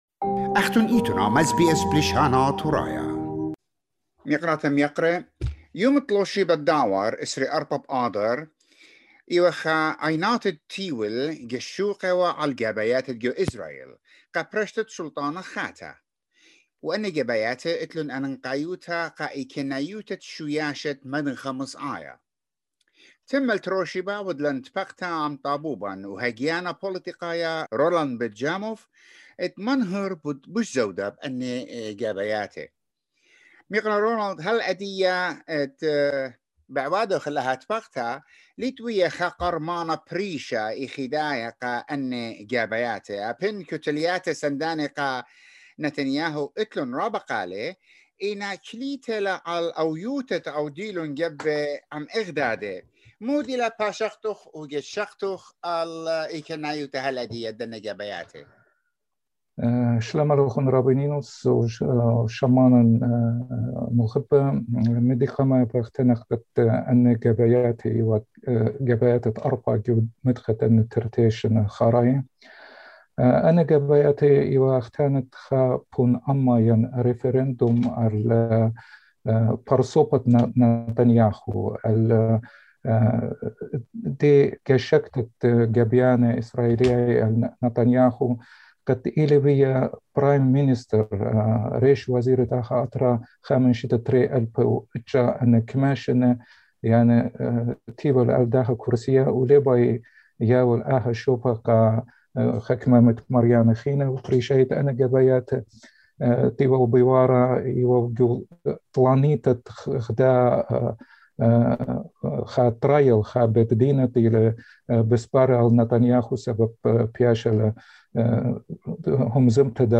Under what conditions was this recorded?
At time of this interview, Israeli election results were 57 seats for anti- Netanyahu block against 52 pro-Netanyahu. Now it's up to two parties to decide the winner.